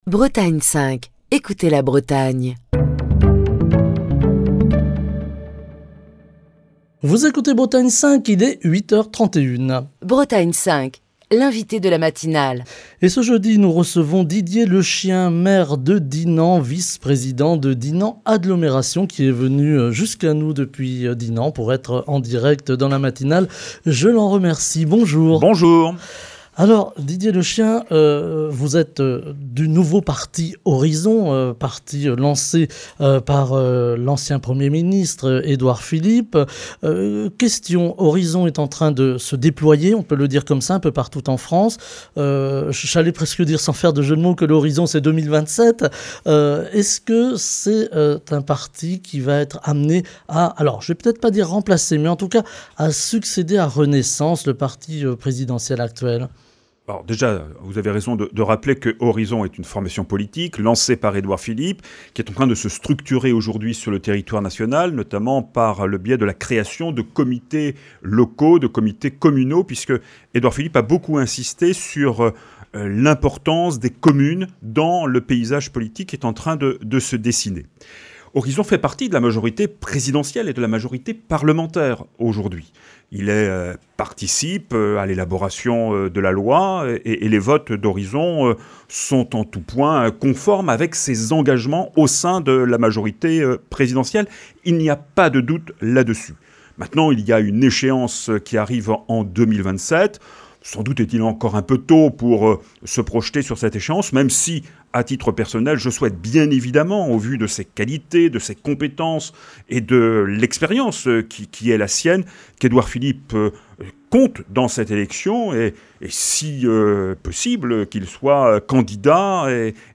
Ce jeudi, Didier Lechien, maire de Dinan, vice-président de Dinan Agglomération, membre d'Horizons est l'invité de la matinale de Bretagne 5.